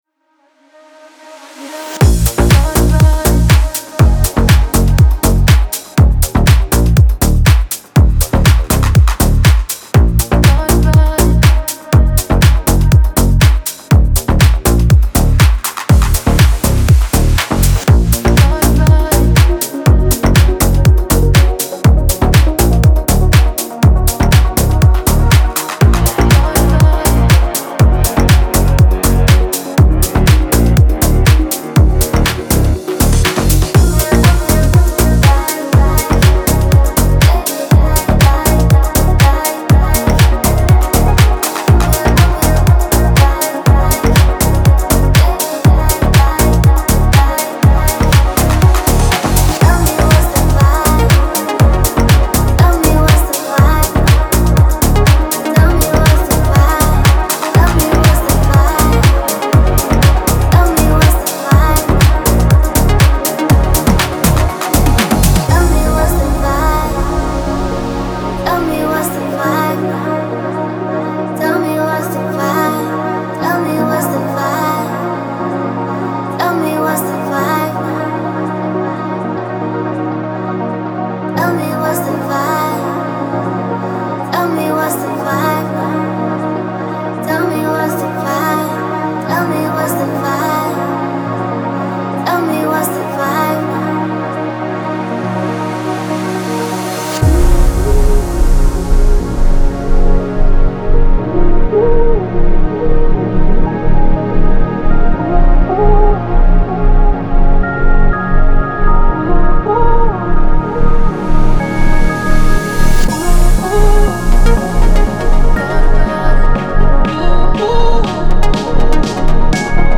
dance
Веселая музыка